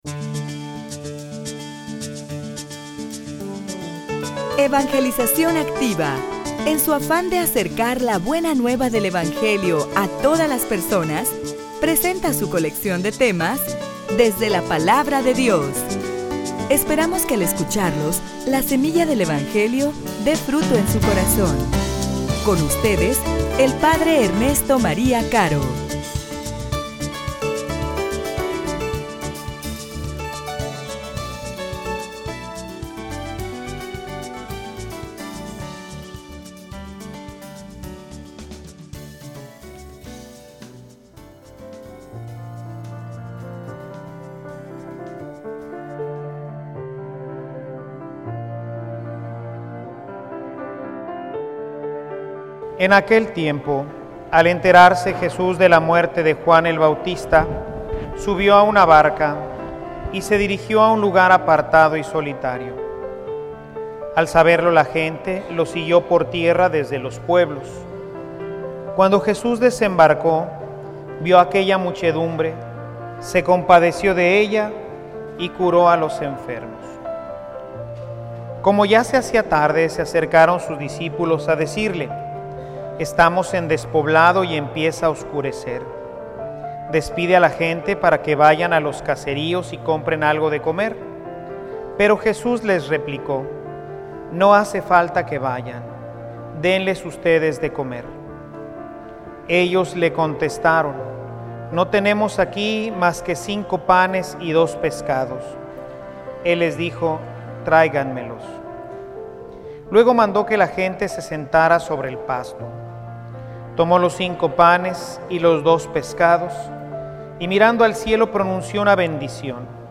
homilia_Invierte_bien_tu_tiempo.mp3